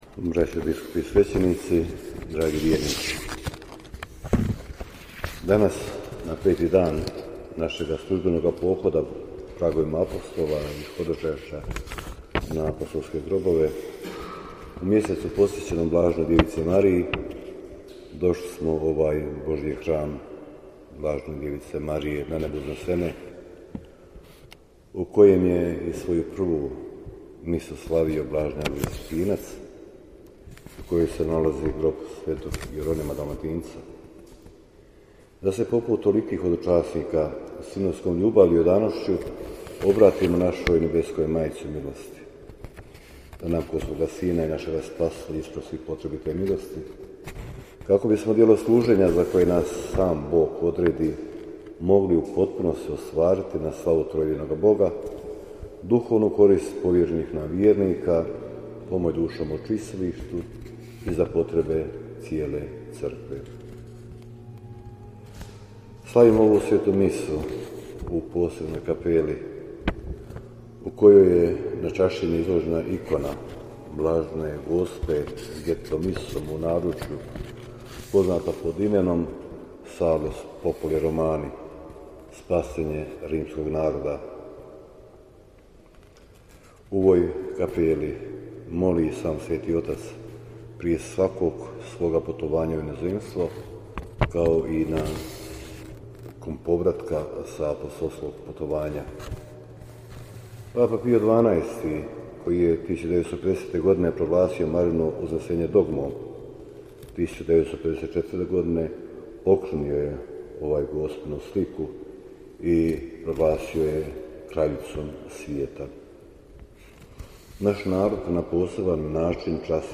Audio: Propovijed biskupa Majića na Svetoj misi biskupa Biskupske konferencije BiH u Bazilici Svete Marije Velike u okviru pohoda „ad limina“ - BANJOLUČKA BISKUPIJA